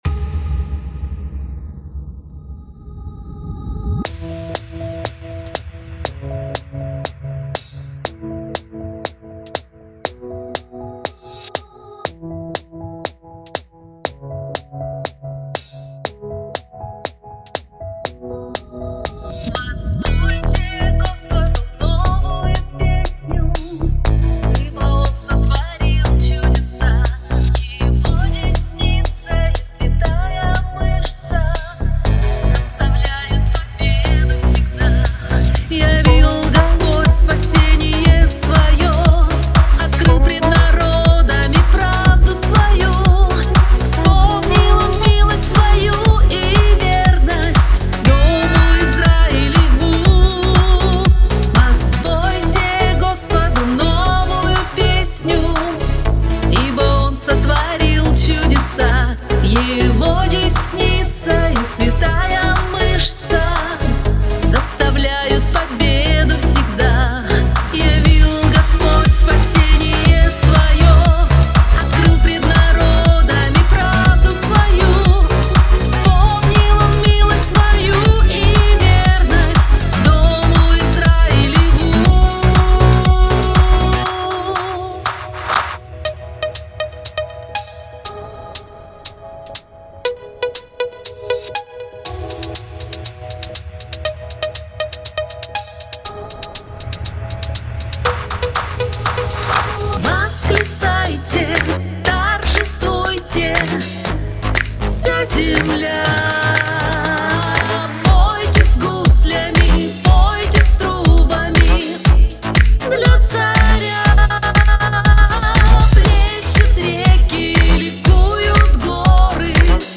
426 просмотров 544 прослушивания 45 скачиваний BPM: 120